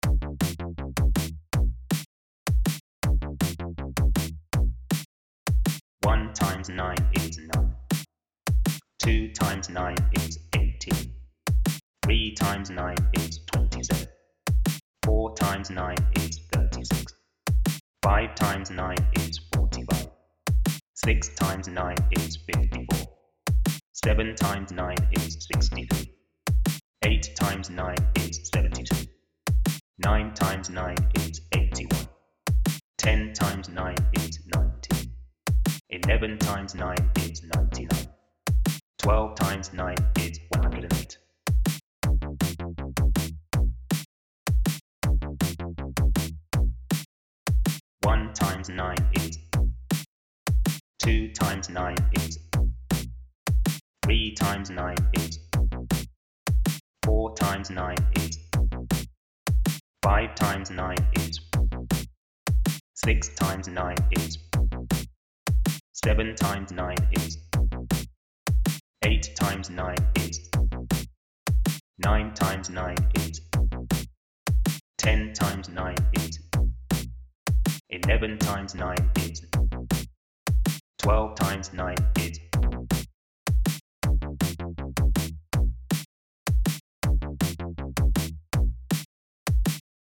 Hear the 9 Times Tables and then answer in time with the music with MathsbyBPM.
9x-Times-Tables-Audio-with-Squlech-4-160bpm.mp3